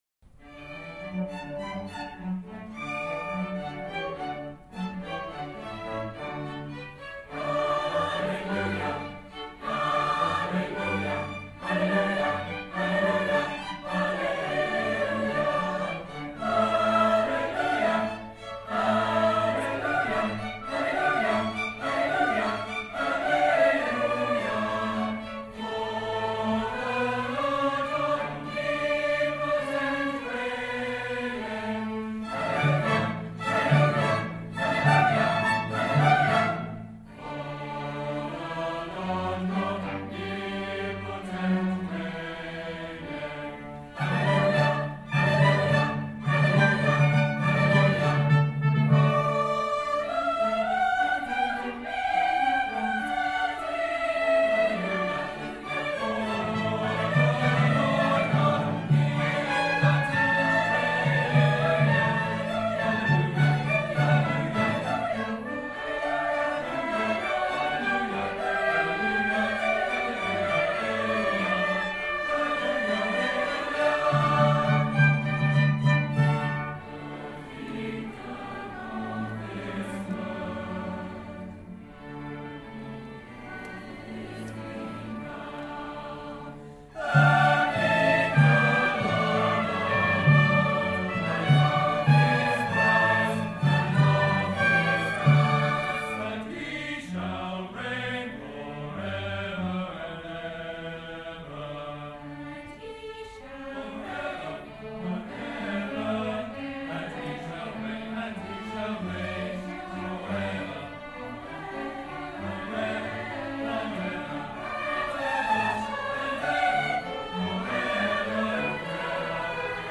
The school orchestra and Take Note, our Adult Choir